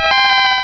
pokeemerald / sound / direct_sound_samples / cries / flaaffy.aif